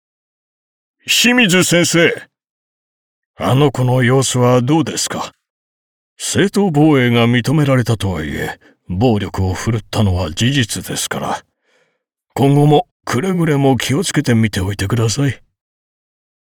ボイスサンプル
• セリフ：中年～高齢男性、威厳、渋め